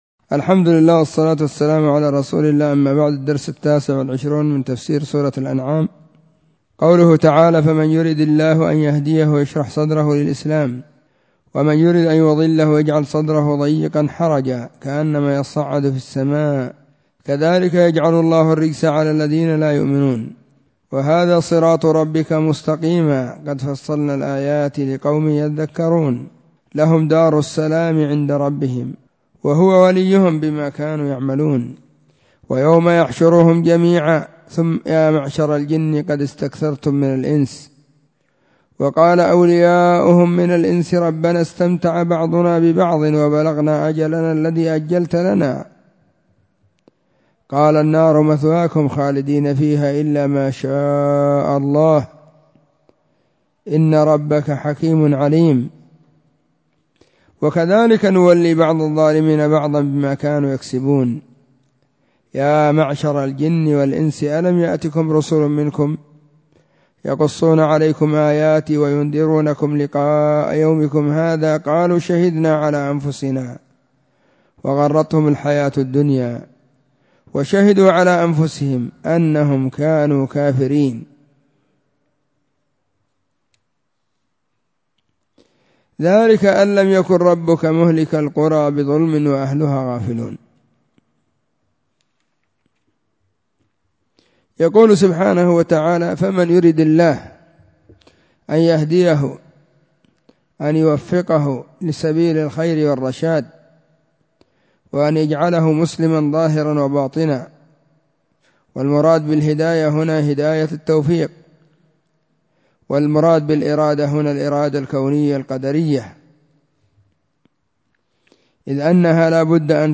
🕐 [بعد صلاة الظهر]
📢 مسجد الصحابة – بالغيضة – المهرة، اليمن حرسها الله.